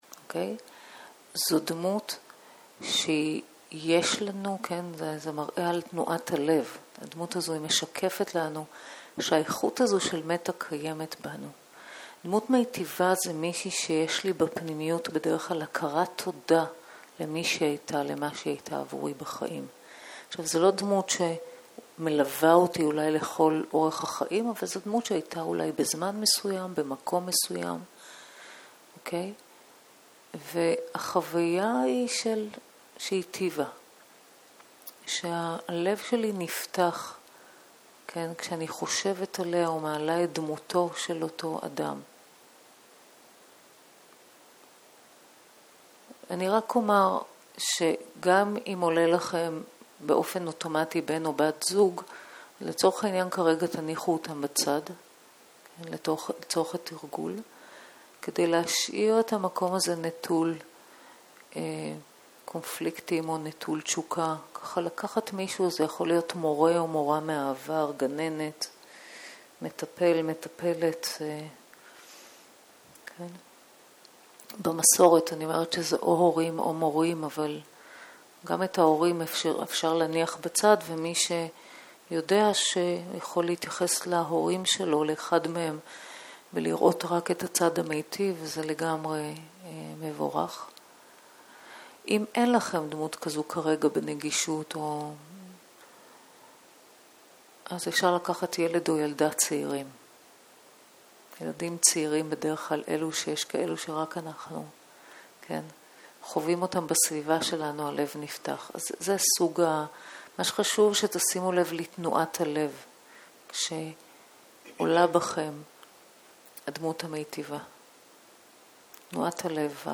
שיחת הנחיות למדיטציה